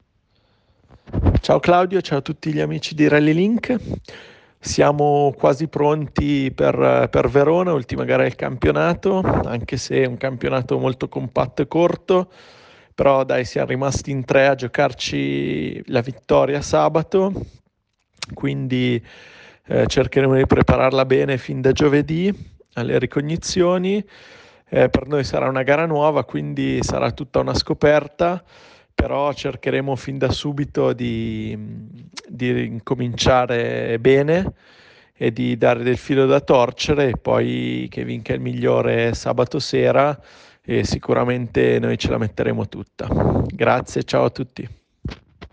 Interviste al Rally Due Valli
Interviste pre-gara